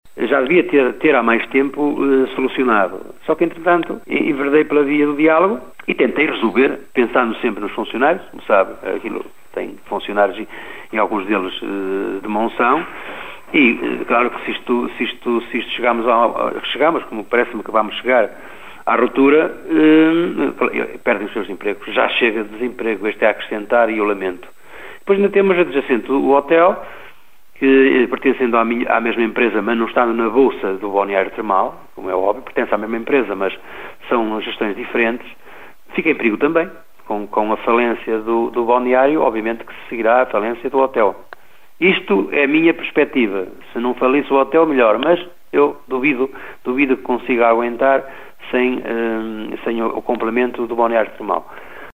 Já há quase um ano que o concessionário do balneário termal não paga renda. O presidente da Câmara de Monção, Augusto Domingues, lamenta pelos empregos que se vão perder e pelo encerramento do hotel que serve o balneário, deixando aquele município sem qualquer unidade hoteleira: